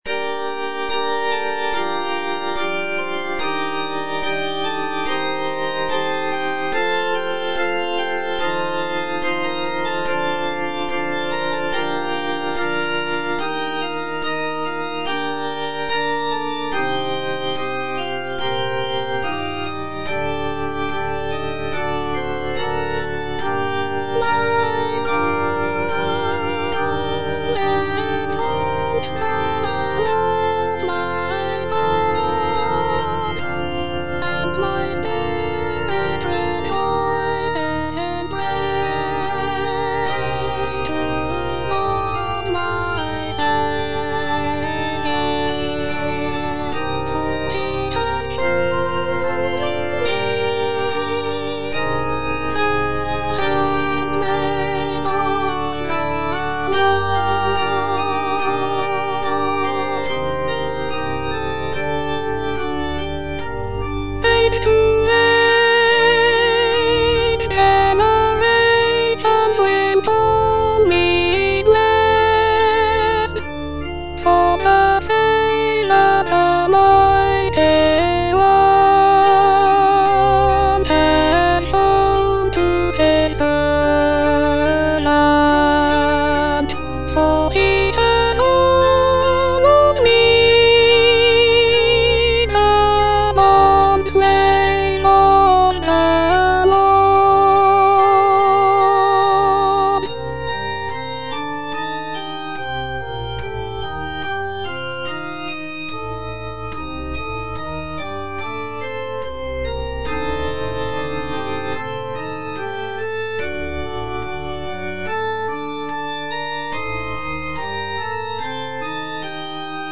Alto (Voice with metronome)
choral piece